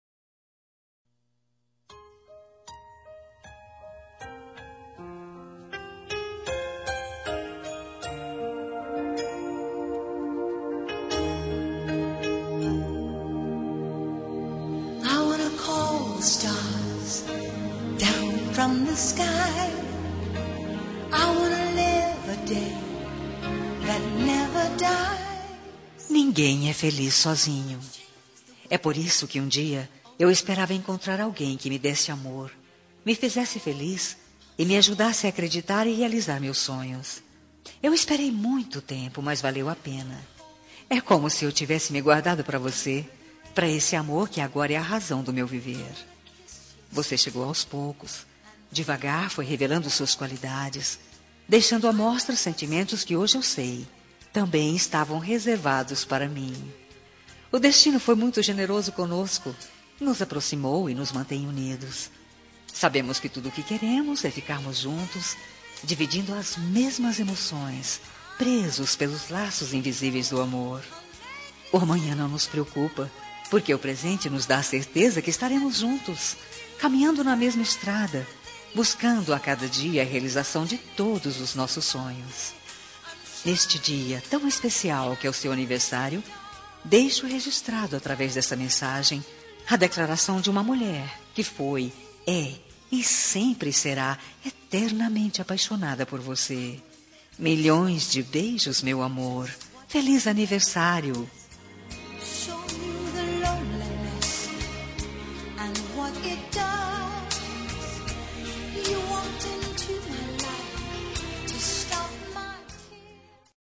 Telemensagem de Aniversário de Marido – Voz Feminina – Cód: 1155